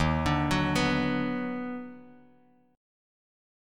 D#mM7#5 chord